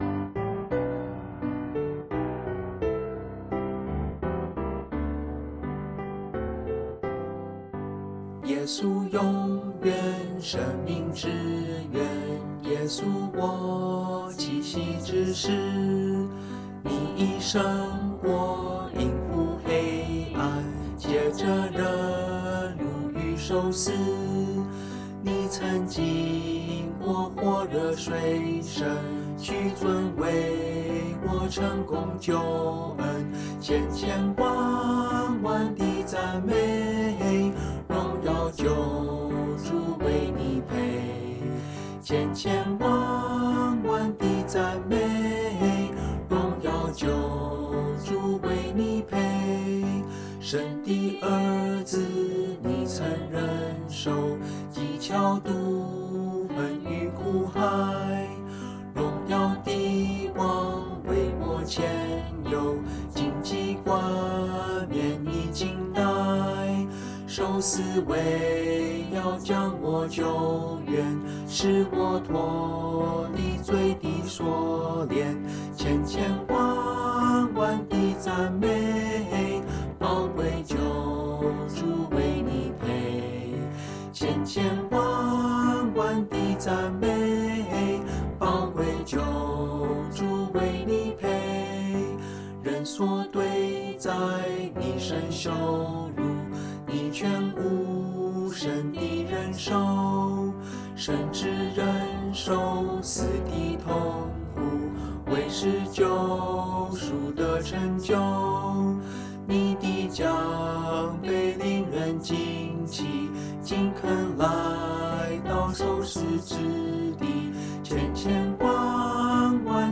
Bb Majeur